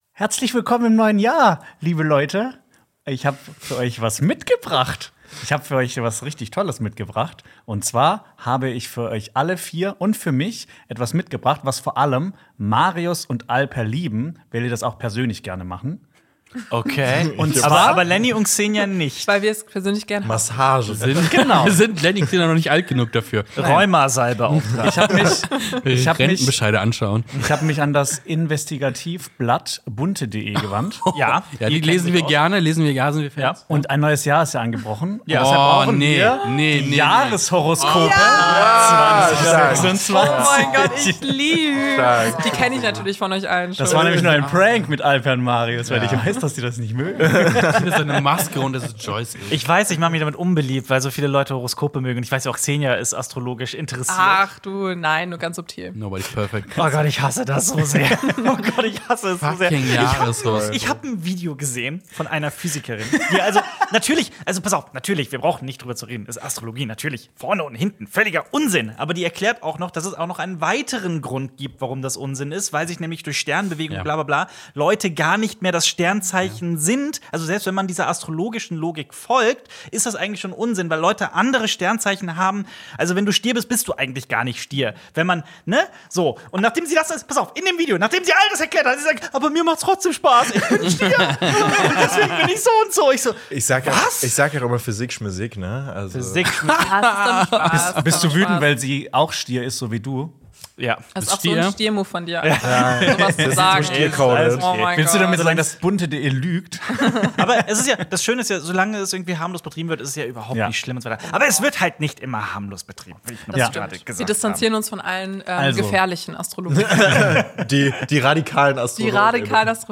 Streamed live 12 hours ago